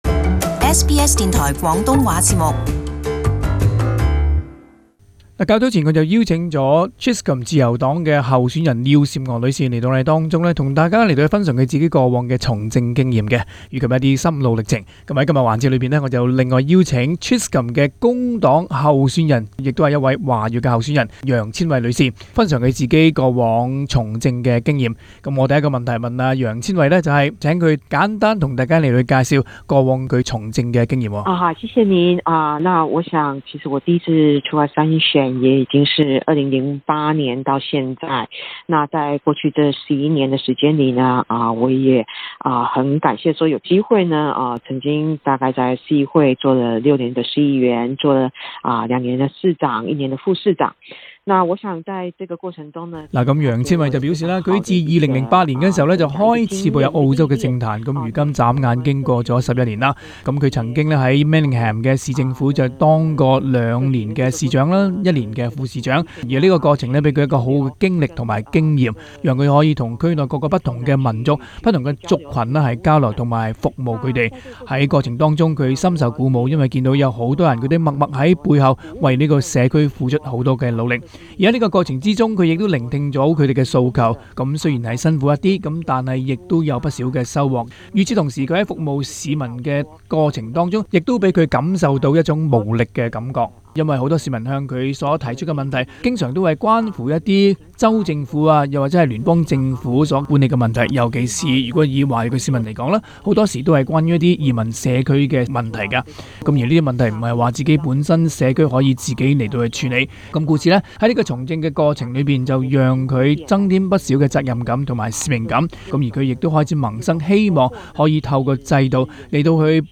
【社區專訪】政壇雙雌爭奪Chisholm議席 鹿死誰手？